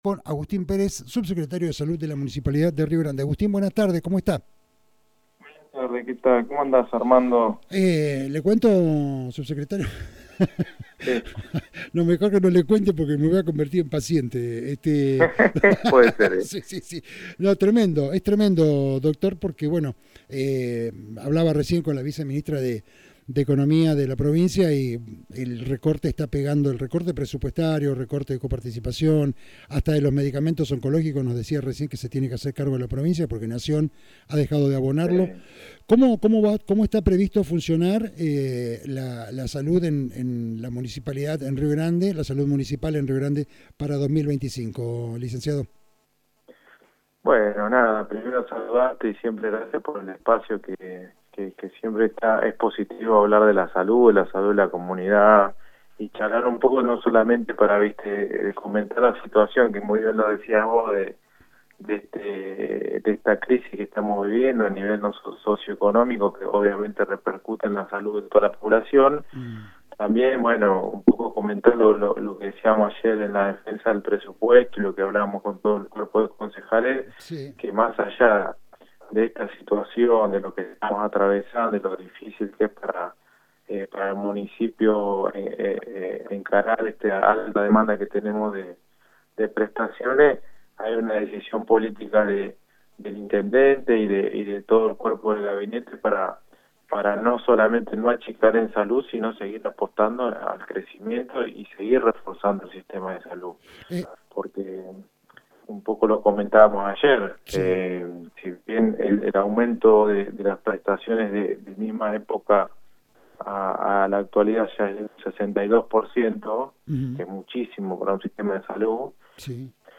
Rio Grande 22/11/2024.- El Subsecretario de Salud de la Municipalidad de Rio Grande, Agustín Perez, en dialogo con Resumen Económico, dio un panorama de la situacion en lo que hace a atención primaria de la salud y el crecimiento de la prestación de servicios por parte del sistema de salud que se han triplicado a partir de este año debido a la situacion económica, la perdida de obras sociales, la caída del empleo y los costos del sector privado.